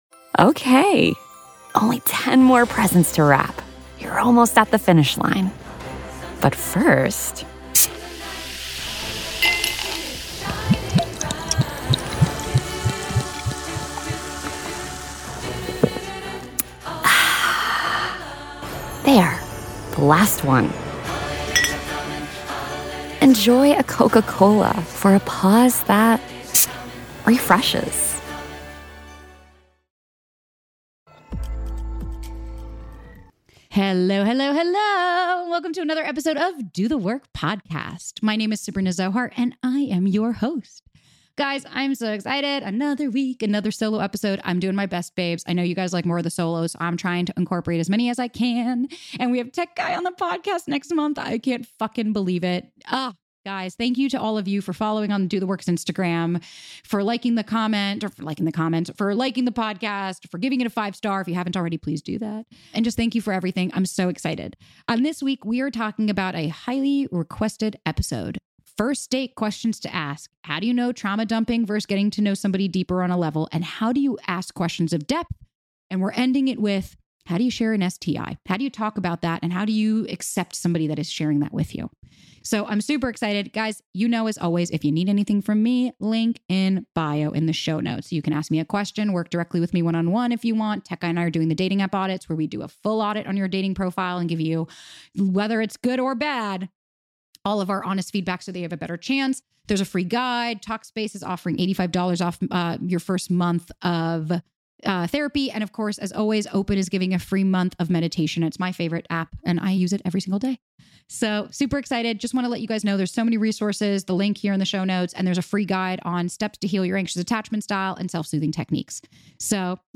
On this weeks solo episode